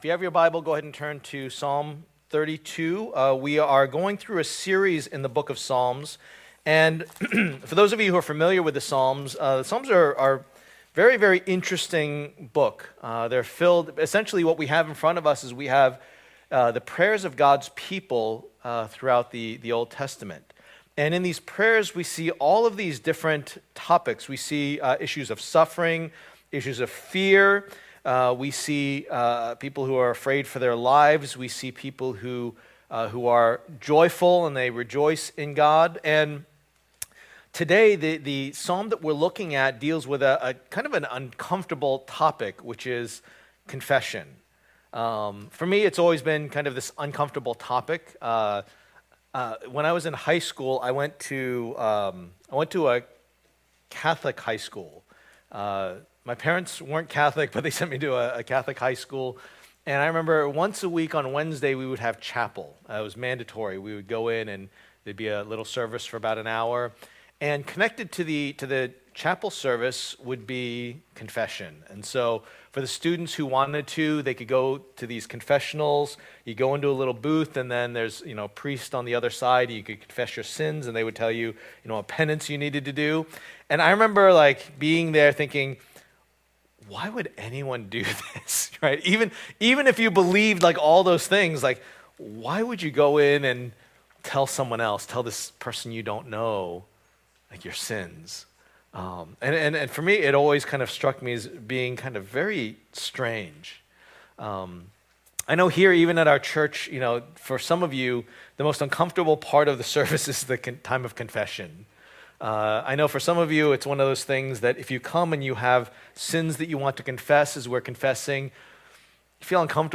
Preacher
Service Type: Lord's Day